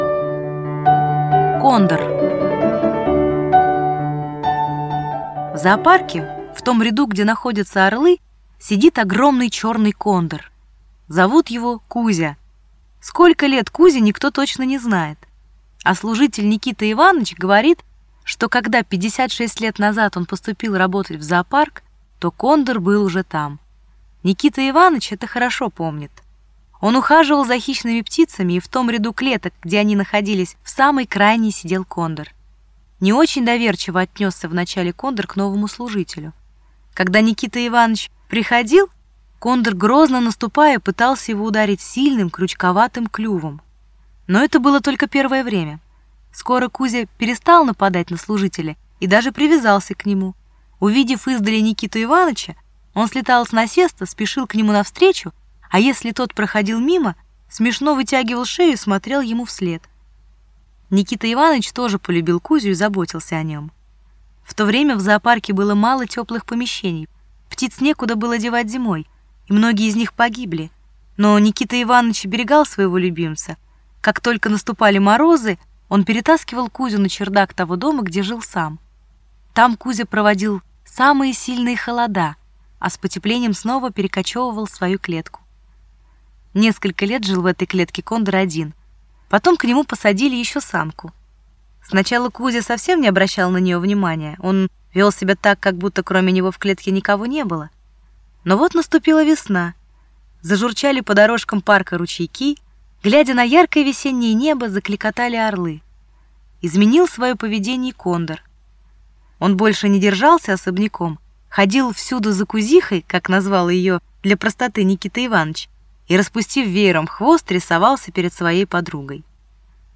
Аудиорассказ «Кондор»